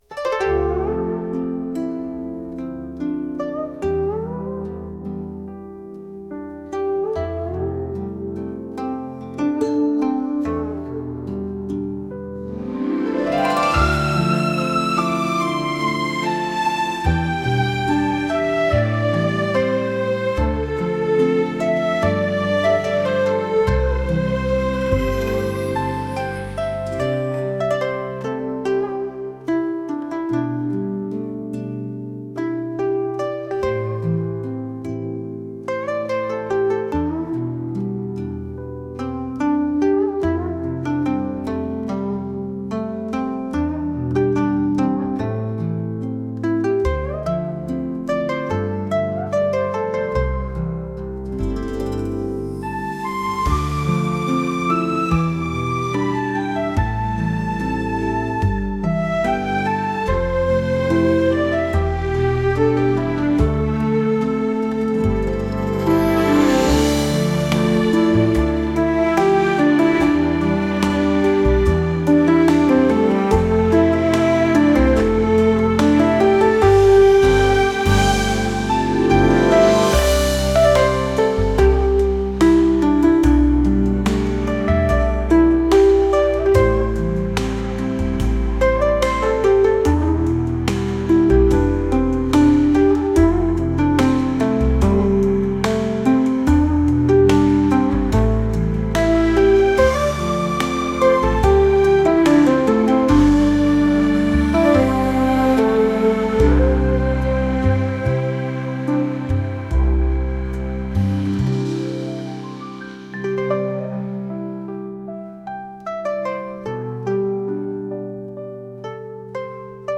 故郷を思い出してしまうような中華風の音楽です。